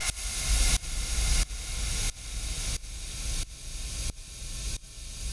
Index of /musicradar/sidechained-samples/90bpm